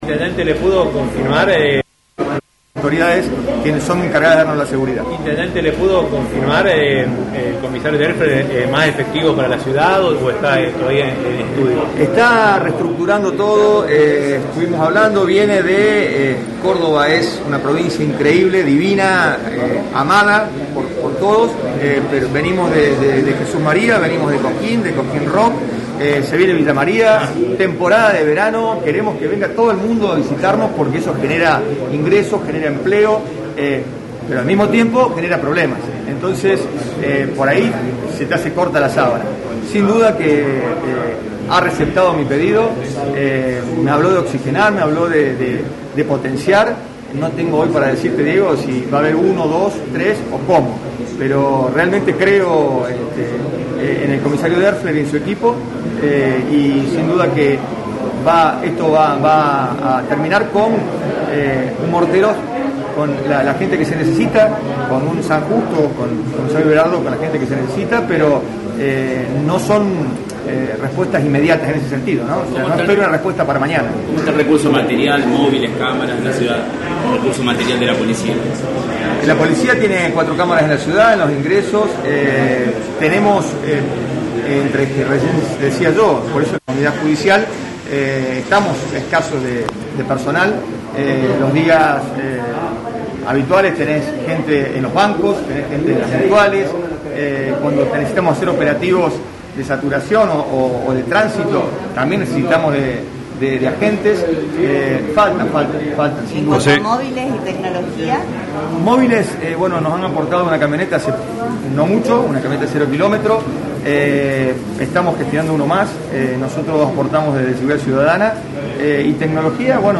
En dialogo con LA RADIO 102.9, Bría fue contundente para reflejar la preocupación al asegurar «hemos tenidos dos códigos mafiosos entre narcos que han venido a nuestra ciudad y eso nos preocupa por eso queremos atacar la problematica en seguida y hemos pedido ayuda…».